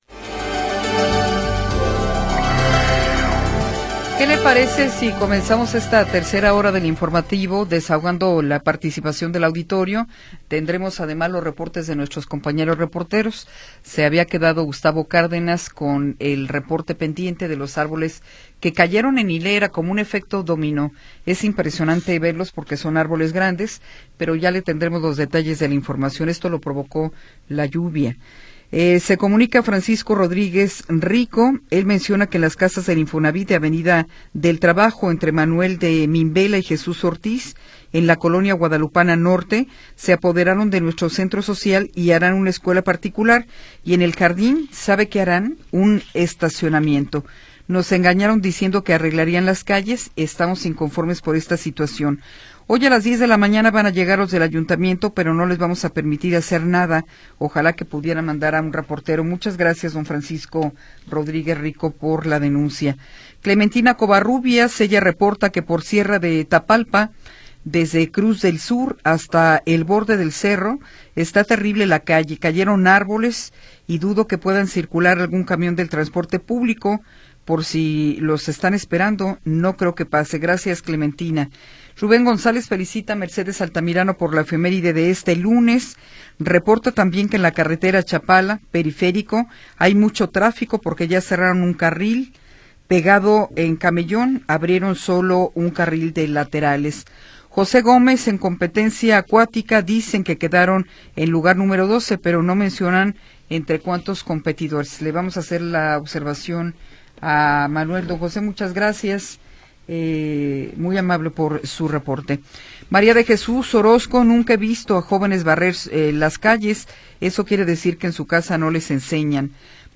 Tercera hora del programa transmitido el 4 de Agosto de 2025.